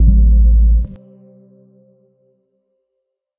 Synth Impact 12.wav